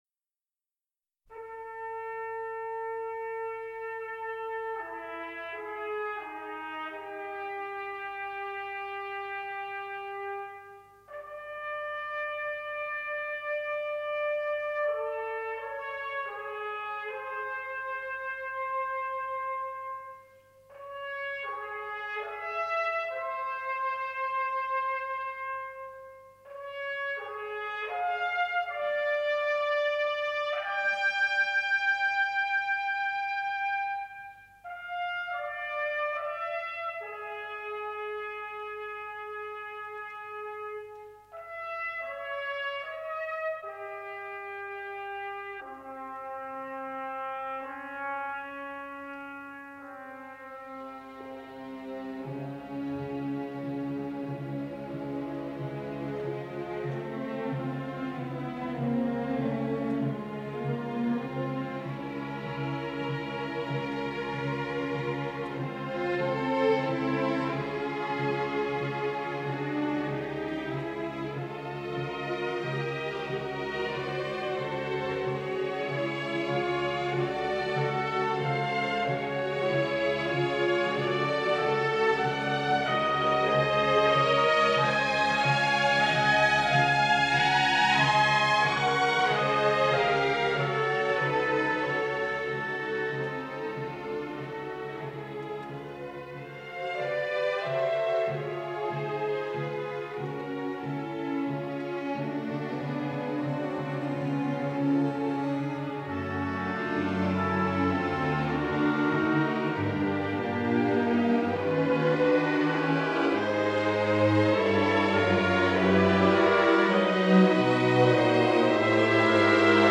in this radio studio concert from 1964.